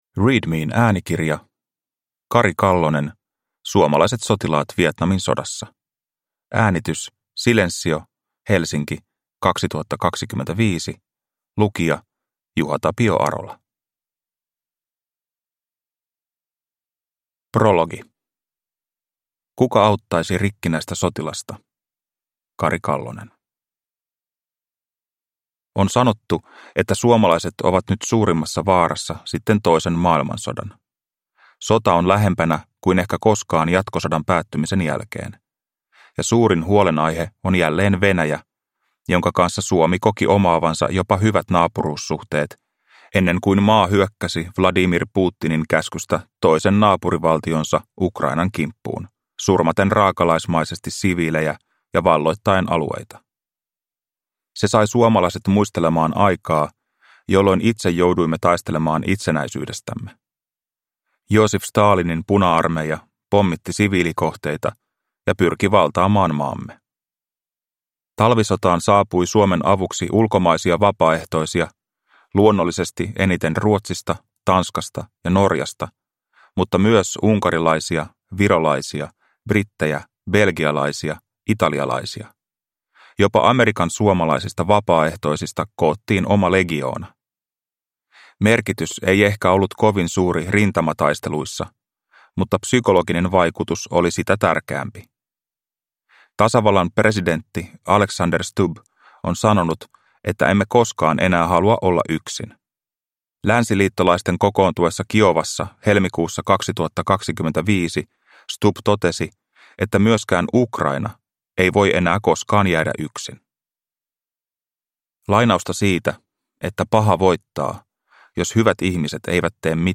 Suomalaiset sotilaat Vietnamin sodassa – Ljudbok